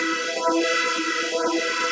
SaS_MovingPad03_125-E.wav